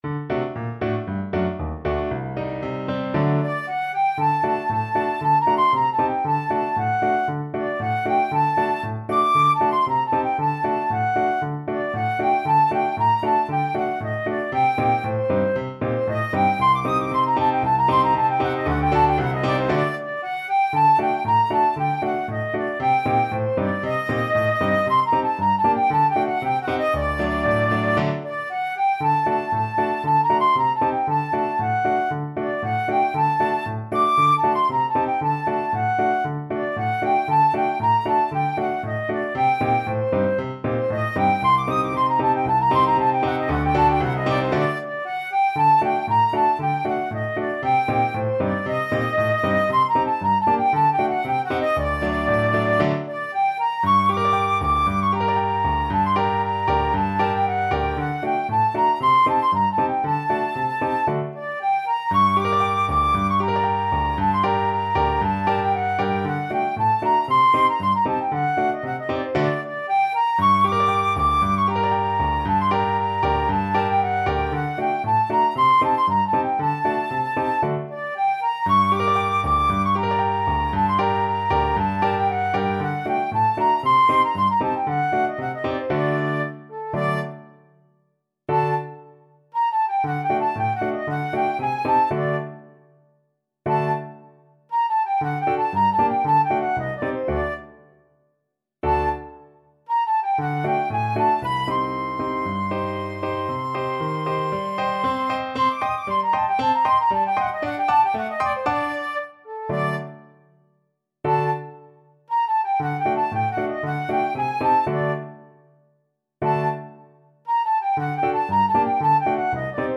Flute
2/4 (View more 2/4 Music)
G minor (Sounding Pitch) (View more G minor Music for Flute )
Allegro =c.116 (View more music marked Allegro)
Traditional (View more Traditional Flute Music)